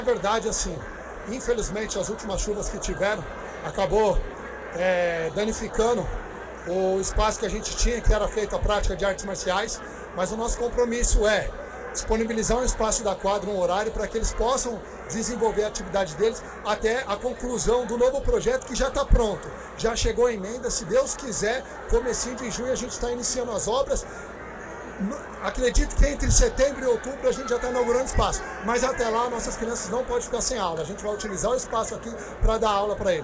No dia 17 de maio, durante a reinauguração o prefeito, em entrevista à imprensa disse: “O projeto já está pronto, já chegou a emenda, se Deus quiser, em junho já estaremos iniciando as obras e entre setembro e outubro já estaremos inaugurando o espaço”, prometeu o prefeito engº Daniel.
Ouça a promessa do prefeito:
entrevista-daniel-judo2.mp3